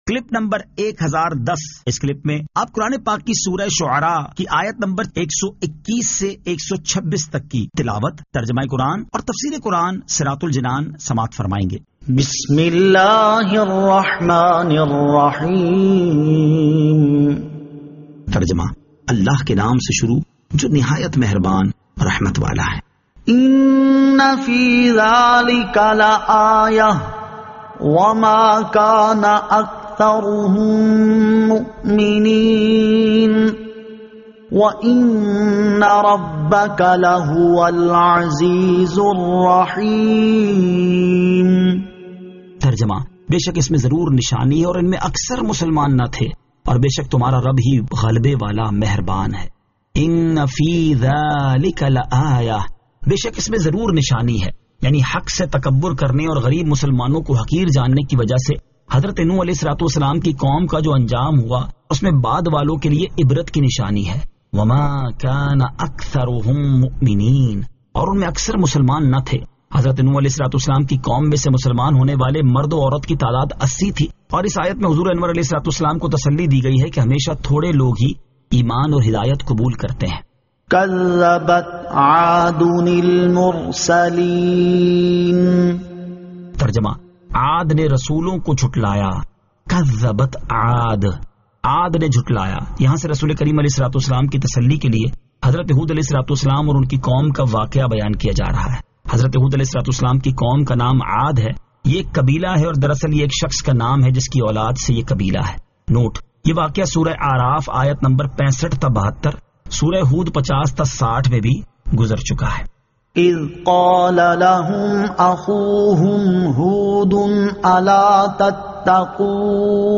Surah Ash-Shu'ara 121 To 126 Tilawat , Tarjama , Tafseer